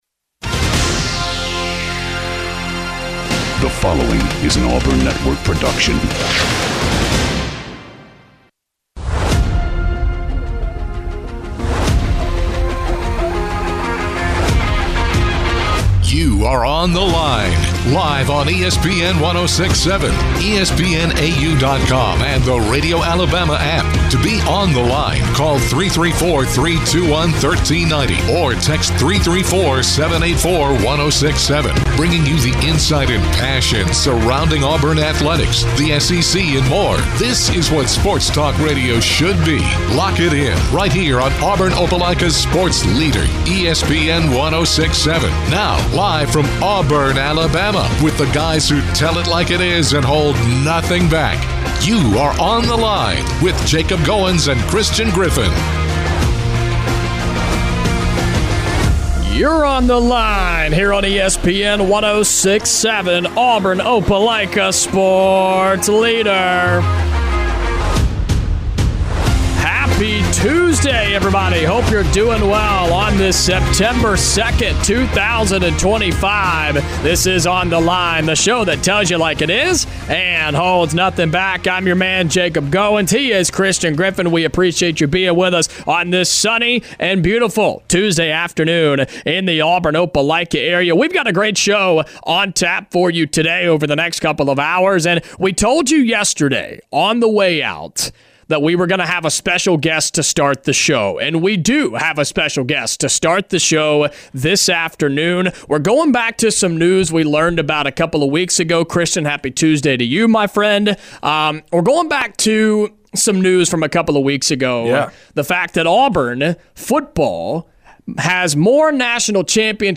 Then, the guys take tons of phone calls getting reactions from Auburn fans after beating Baylor